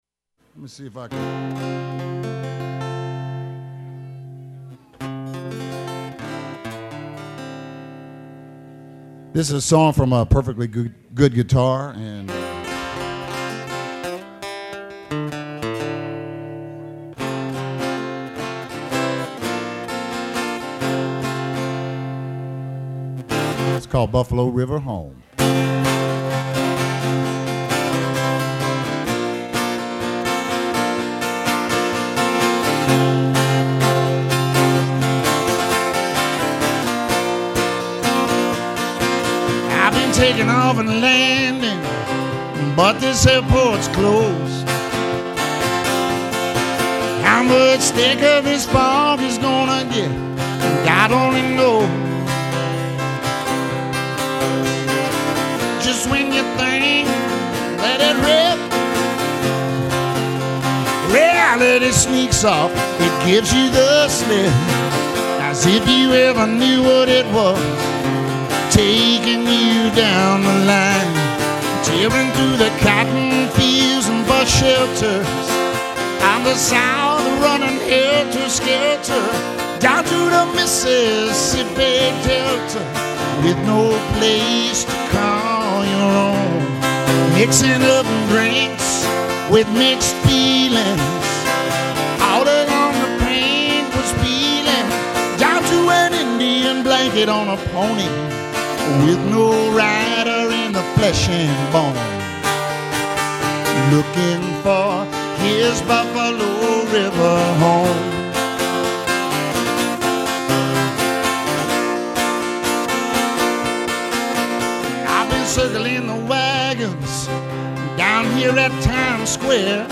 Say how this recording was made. one-man band version